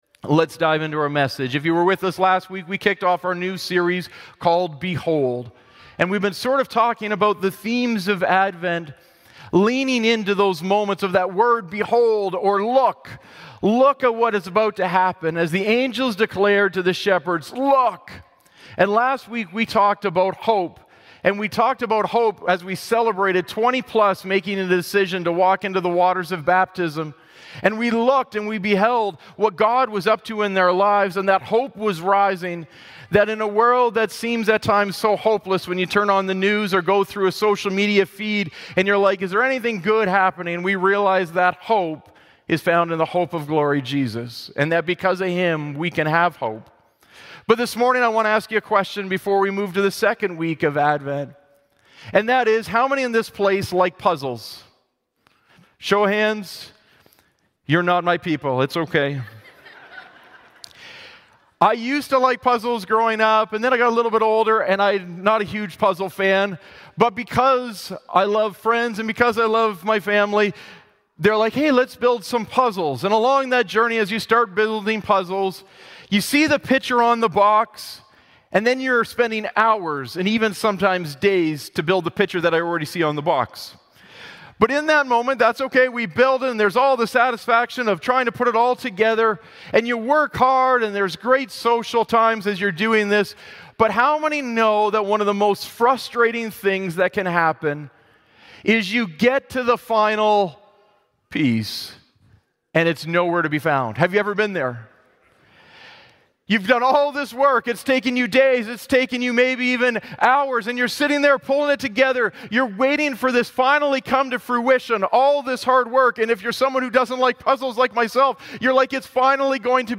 Glad Tidings Church (Sudbury) - Sermon Podcast Missing Peace?